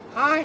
Raven Sound - Hi